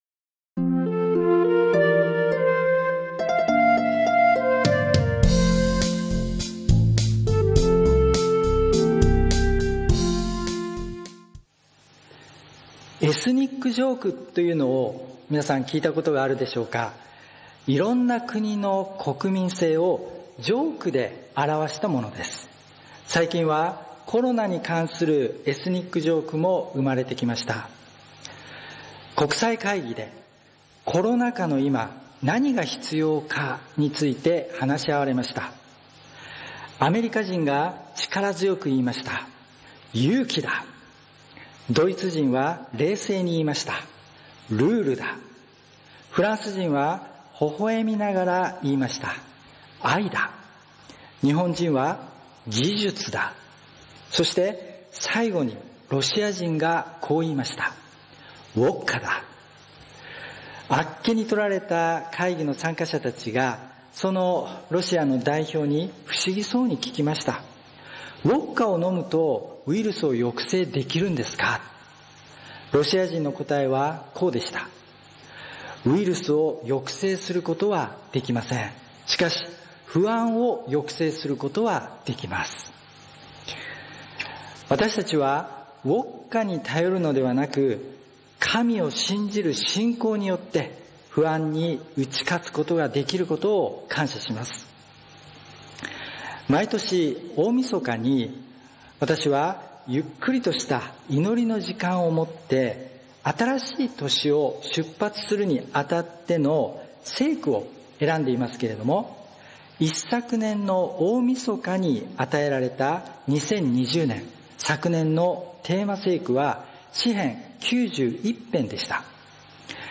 礼拝メッセージ178 祈りの10日間
始めの讃美歌 ：希望の讃美歌 102番 終わりの讃美歌 ：希望の讃美歌 436節 収録：セブンスデー・アドベンチスト立川教会